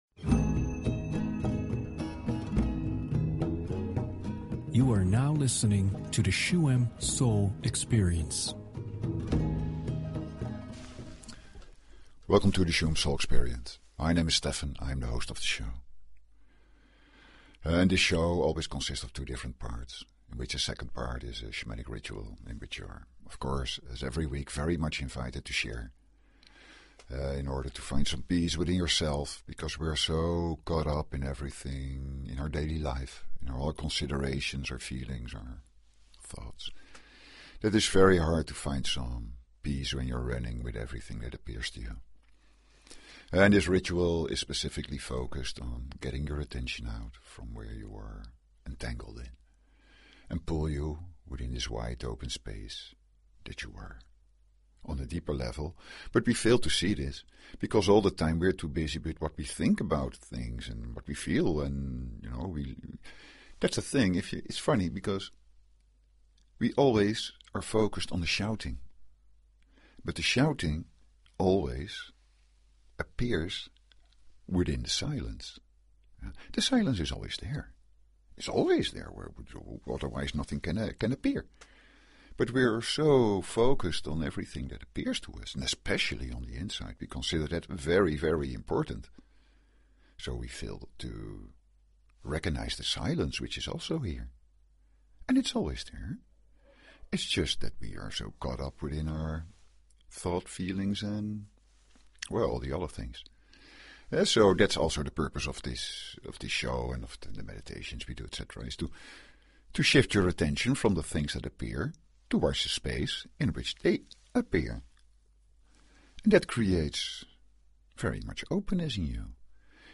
Talk Show Episode, Audio Podcast, Shuem_Soul_Experience and Courtesy of BBS Radio on , show guests , about , categorized as
This episode of Shuem Soul Experience presents a simple exercise that you can use every moment of your life to bring your attention into the here and now. The second part is a Shamanic Meditation that eases this process.